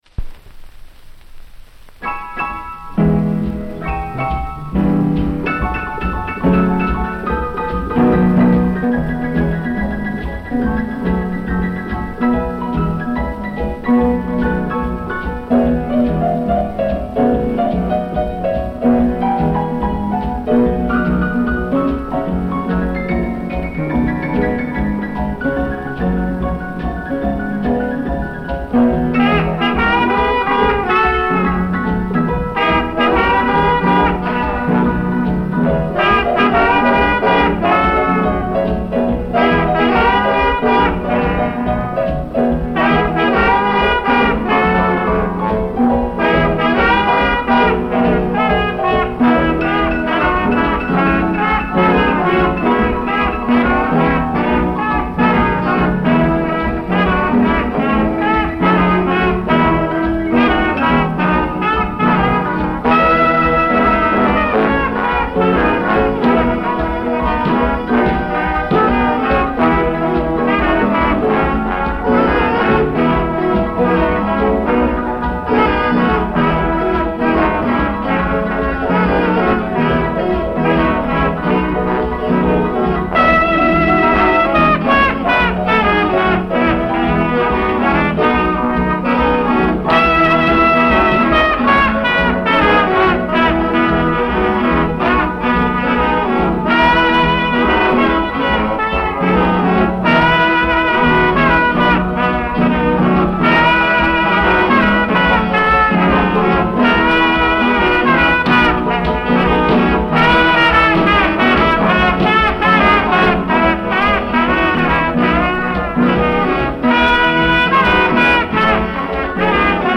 recorded 1953
78 rpm
mono
alto sax
trombone (lead)
tenor sax
trumpet